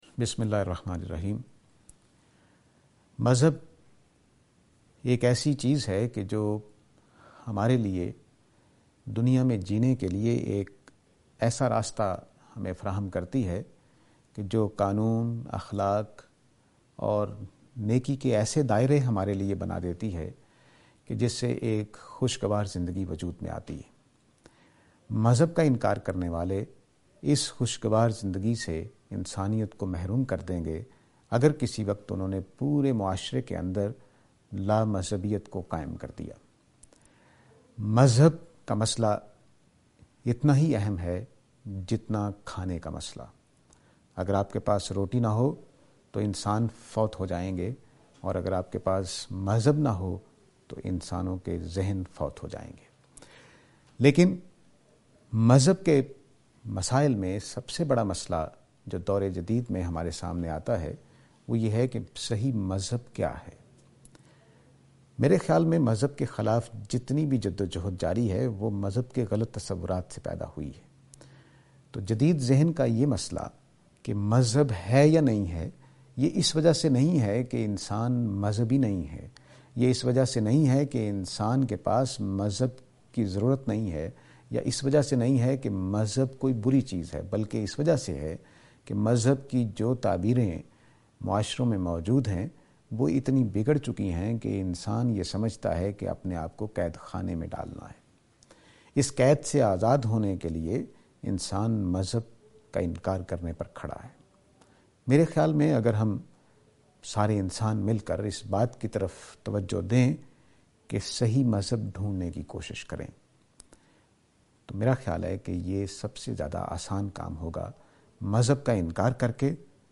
This lecture is and attempt to answer the question "What is Religion?".